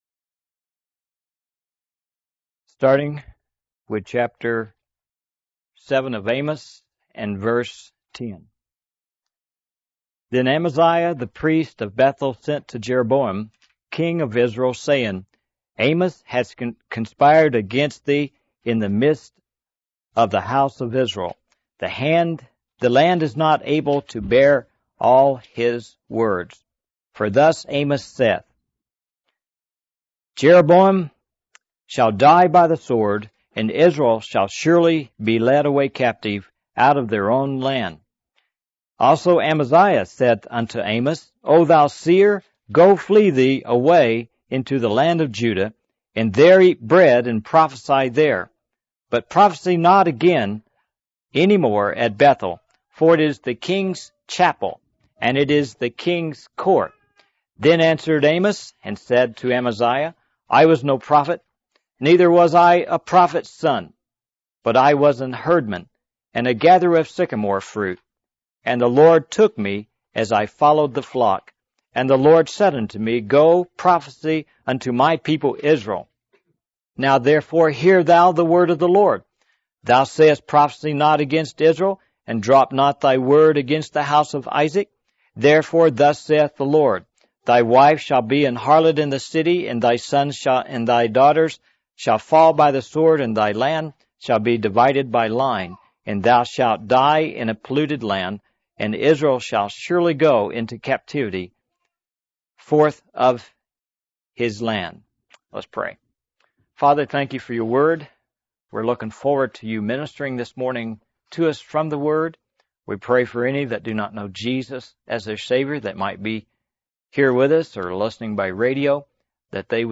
The sermon encourages believers to embrace their calling and to share the hope of the Gospel with others, just as Amos did, while also recognizing the weight of God's burden for His people.